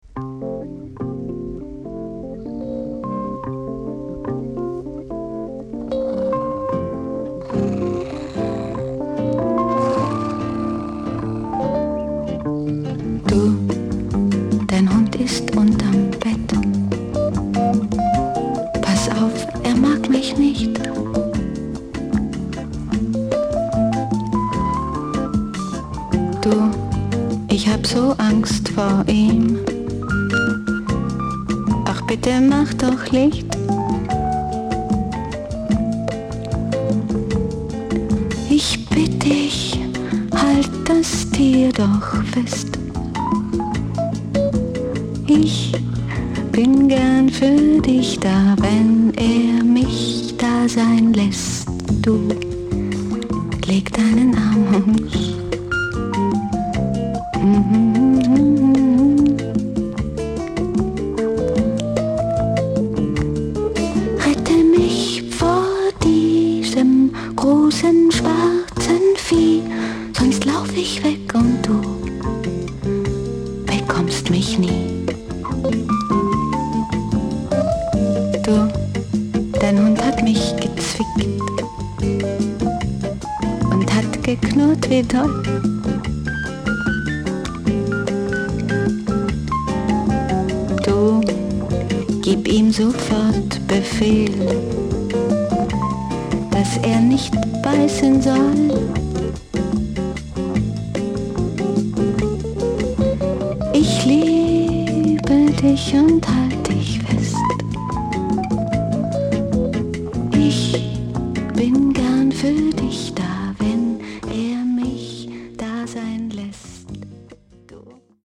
聴き手を夢見心地の気分に誘う逸品。